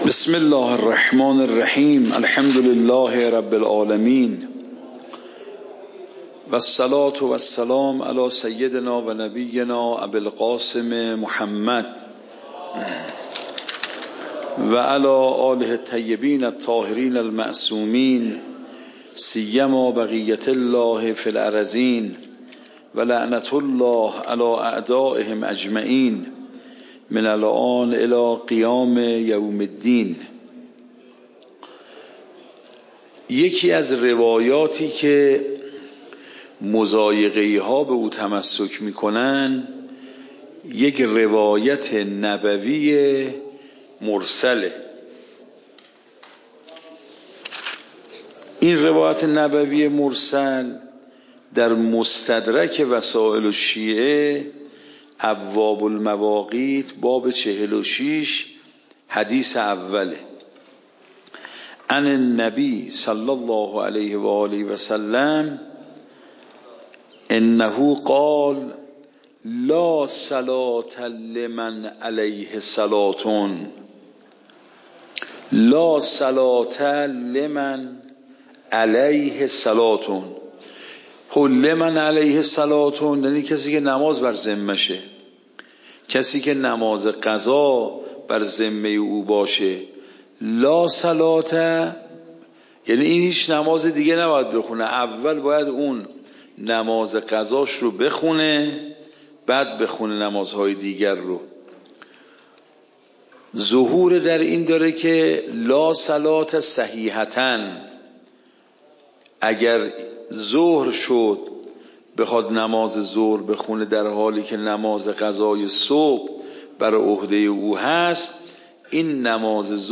درس بعد مواسعه و مضایقه درس قبل مواسعه و مضایقه درس بعد درس قبل موضوع: صلاة قضاء (مواسعه و مضایقه) فقه خارج فقه مباحث صلاة القضاء صلاة قضاء (مواسعه و مضایقه) تاریخ جلسه : ۱۴۰۴/۲/۱ شماره جلسه : ۸۰ PDF درس صوت درس ۰ ۳۴۲